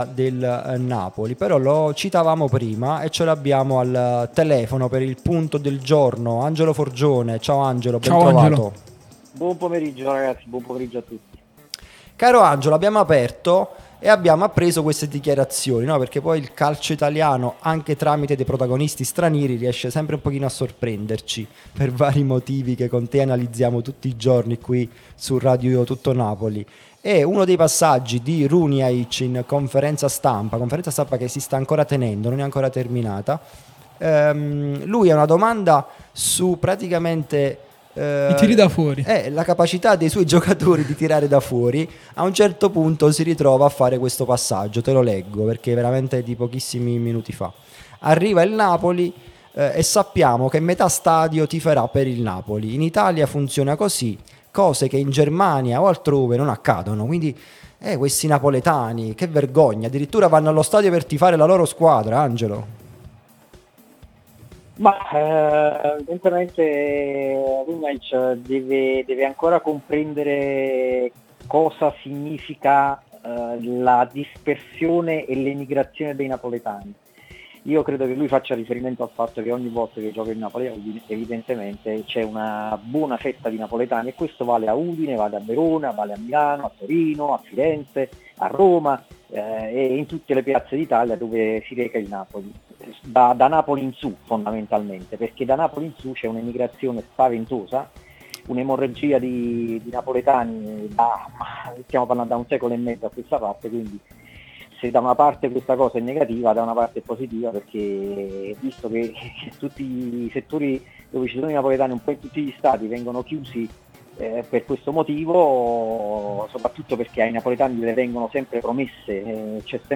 trasmissione sulla nostra Radio Tutto.